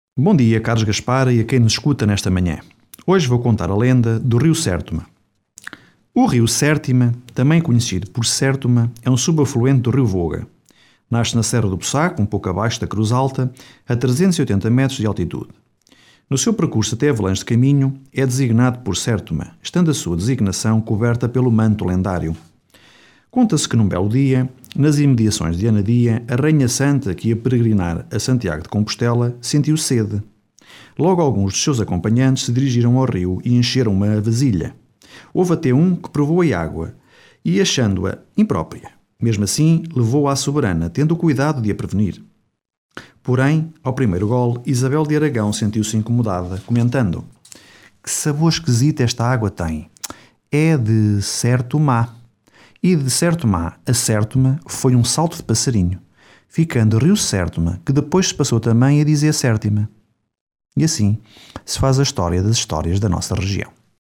Mais uma lenda da nossa Região contada pelo Historiador e Investigador